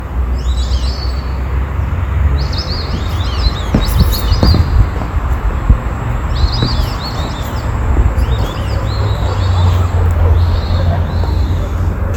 Irerê (Dendrocygna viduata)
Nome em Inglês: White-faced Whistling Duck
Fase da vida: Adulto
Localidade ou área protegida: Mar del Plata
Condição: Selvagem
Certeza: Gravado Vocal